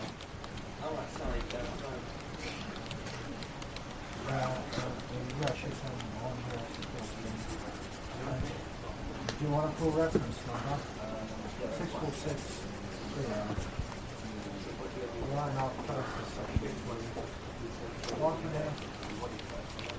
Free Ambient sound effect: Library Quiet.
Library Quiet
# library # quiet # indoor About this sound Library Quiet is a free ambient sound effect available for download in MP3 format.
397_library_quiet.mp3